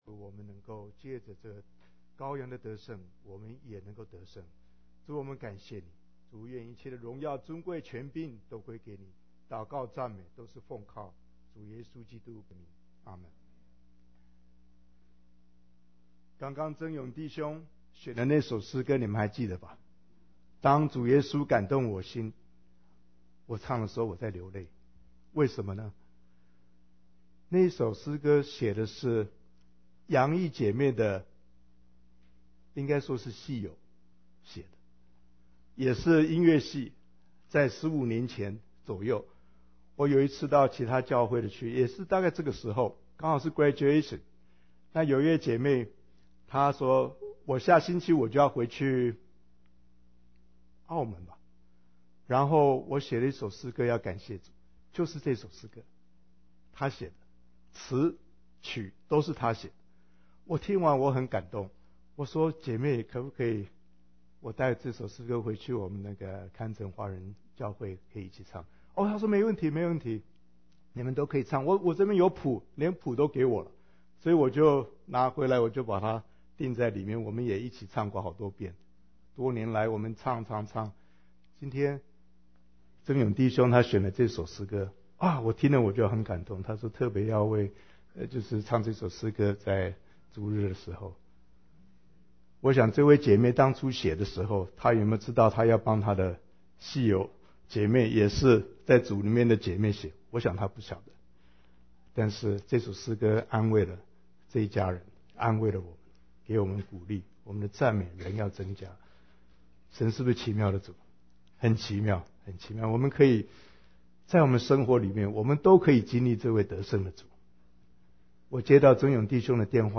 Sermon Podcasts Downloads | Greater Kansas City Chinese Christian Church (GKCCCC)